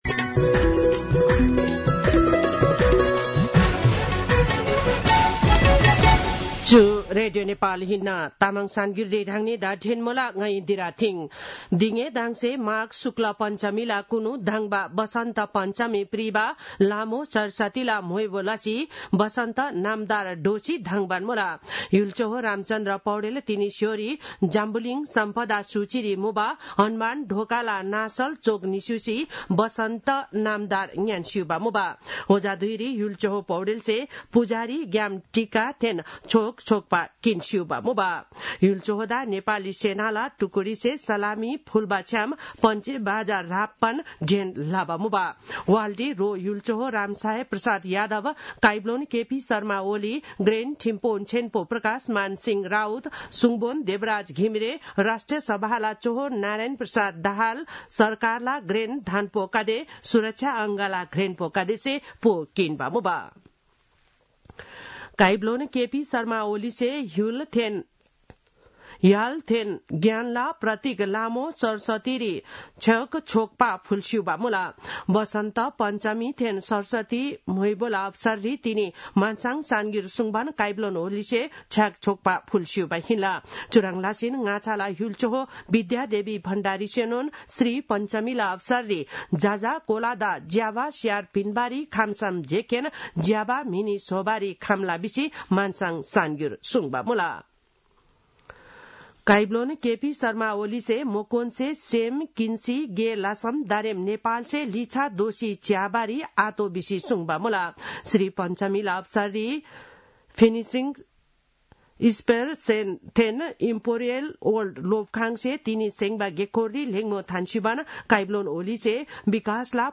तामाङ भाषाको समाचार : २२ माघ , २०८१